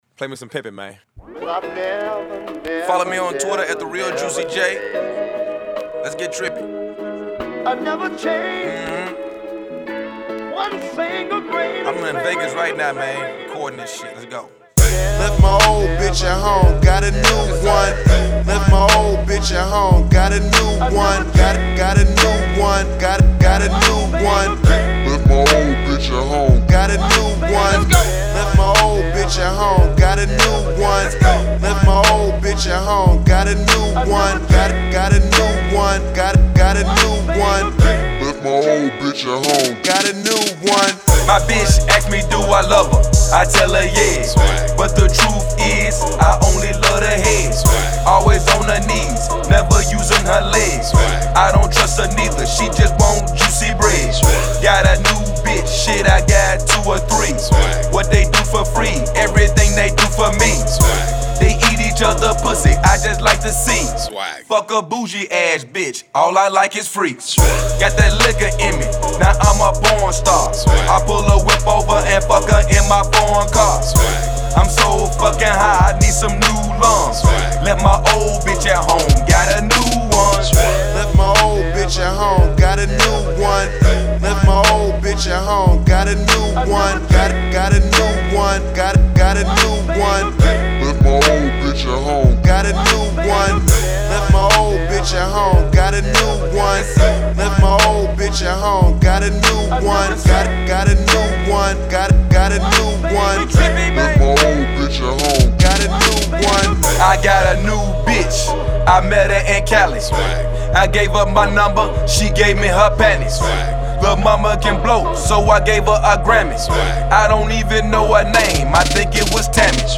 lovin the beat